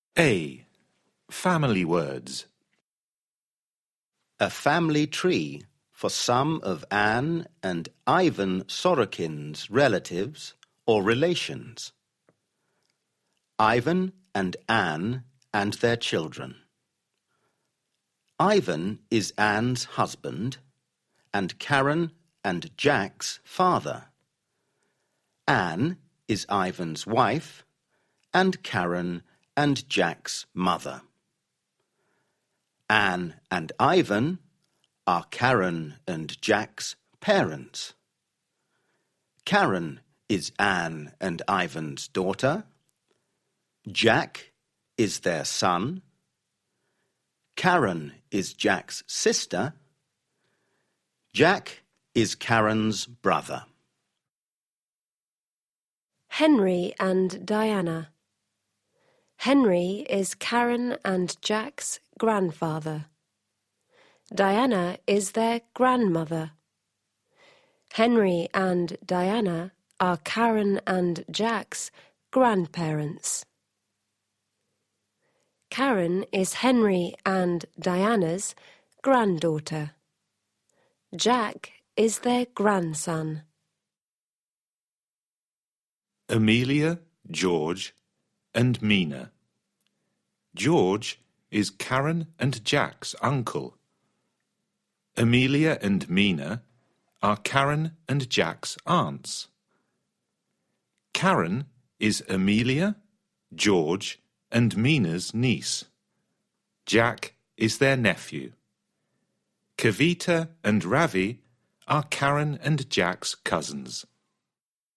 查看文件，两都是单声道的，你上面的命令应该都可以，你听下，分离后跟原来的立体声不一样了